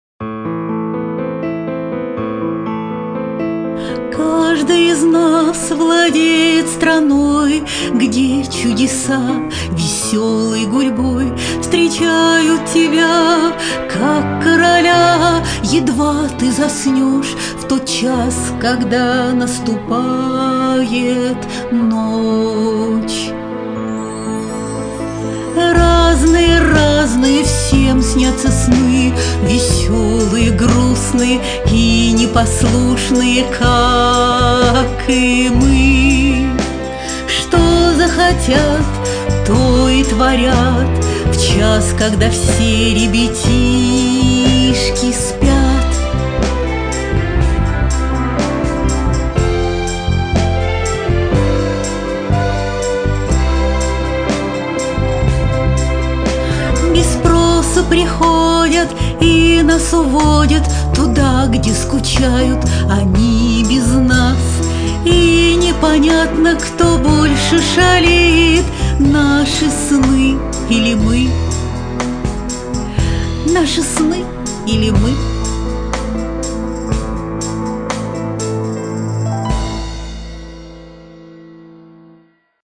— песенка из аудиосказки